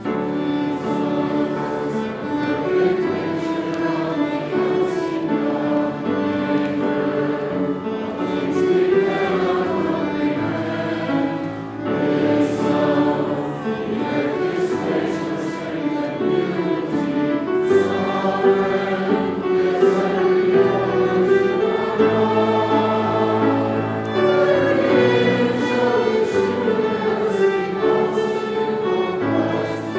John 14:9-14 Tune Key: D Major Used With Text